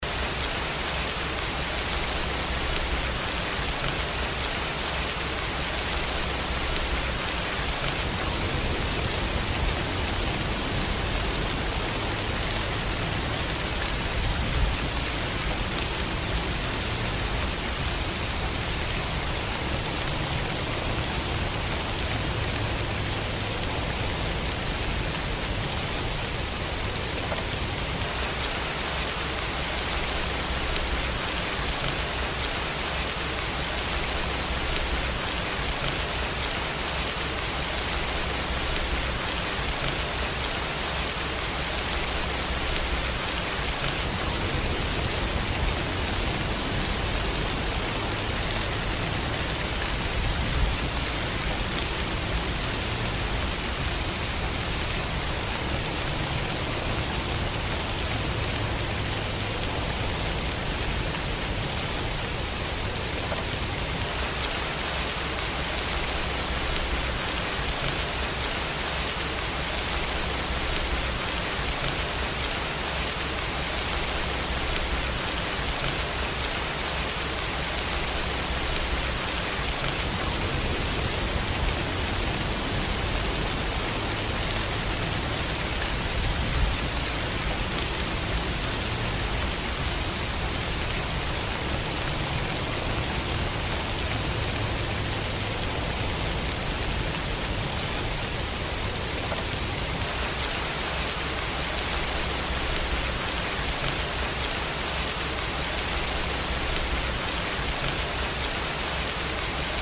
Nature Sounds
Pluie
04.-rain.mp3